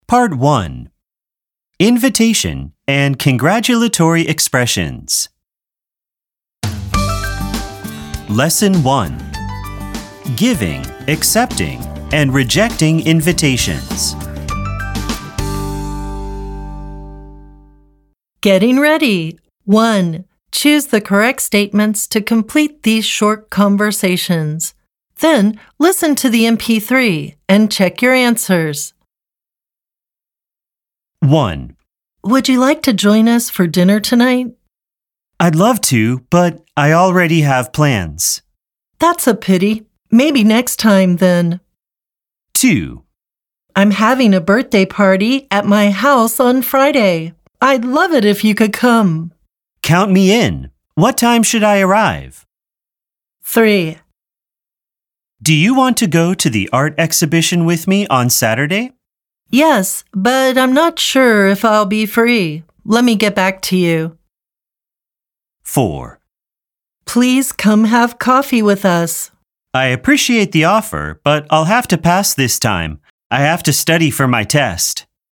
透過與該課主題相關的情境對話，結合字彙和聽力測驗，幫助學生完整學習對話內容。
掃描書封QR Code下載「寂天雲」App，即能下載全書音檔，無論何時何地都能輕鬆聽取專業母語老師的正確道地示範發音，訓練您的聽力。